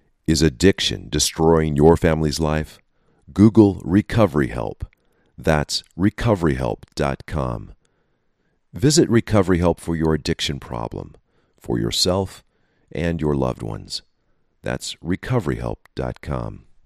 Addictions Help Audio Ad